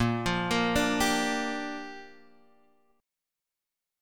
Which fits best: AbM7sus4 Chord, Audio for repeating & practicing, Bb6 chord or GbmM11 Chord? Bb6 chord